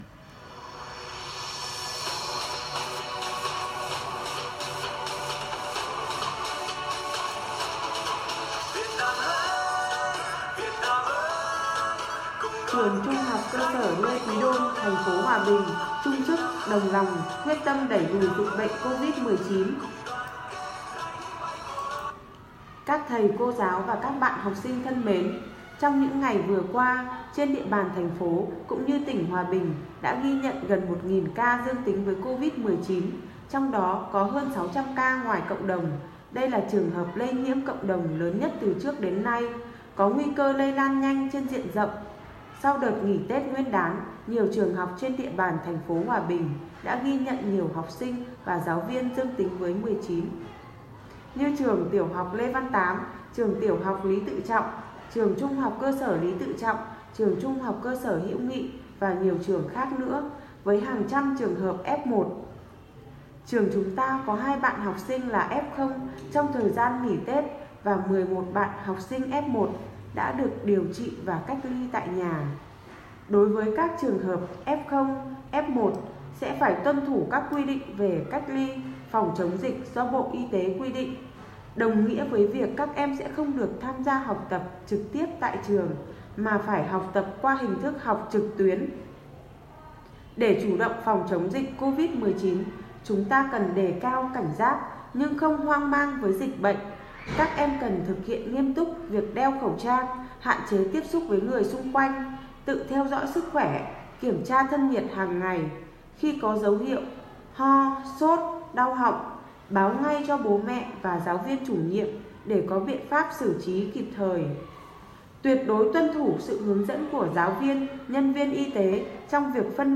BẢN TIN COVID 19 TRƯỜNG THCS LÊ QUÝ ĐÔN
Nhạc dạo